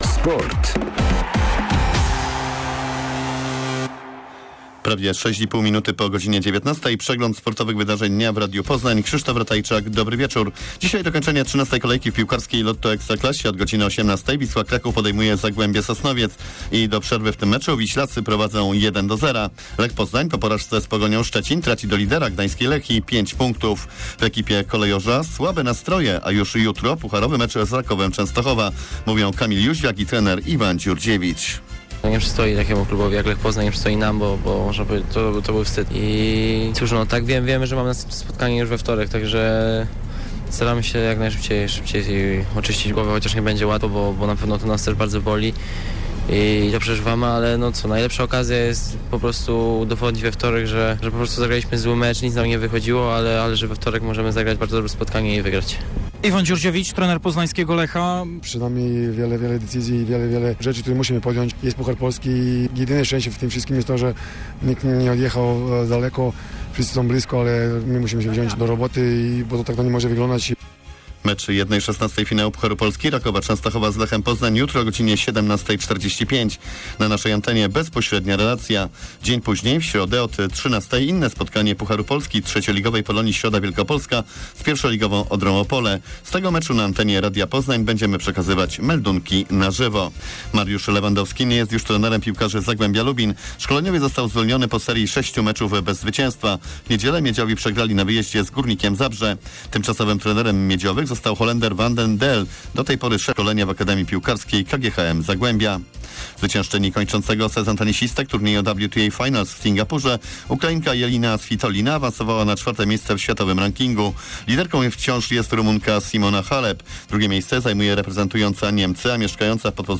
29.10. serwis sportowy godz. 19:05